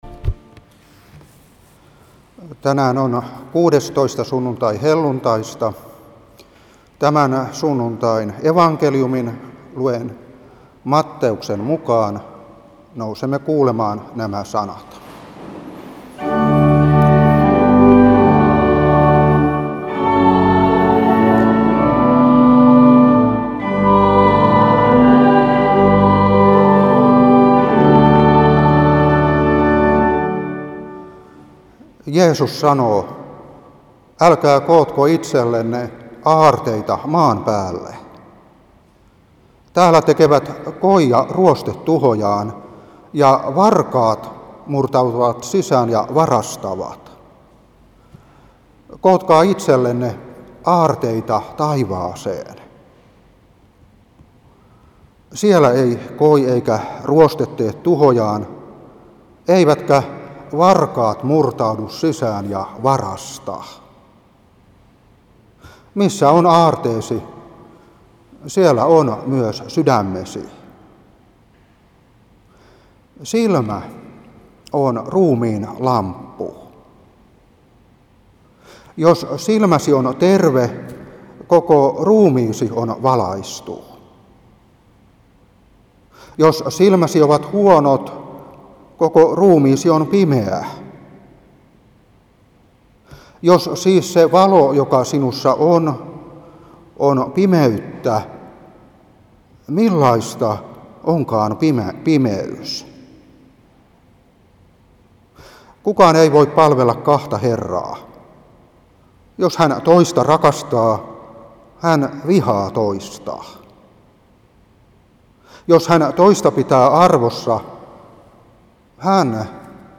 Saarna 2021-9.